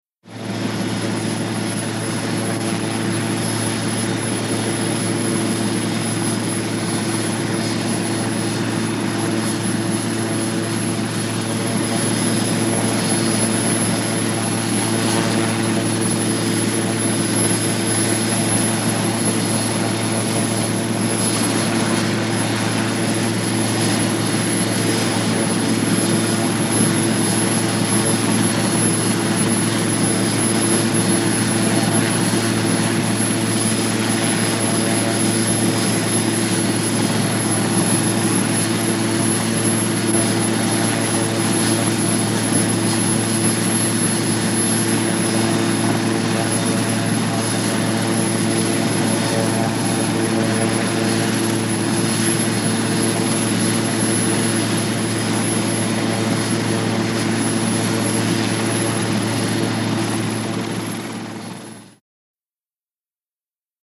HELICOPTER BELL UH-1 IROQUOIS: EXT: Idling with turbine whine.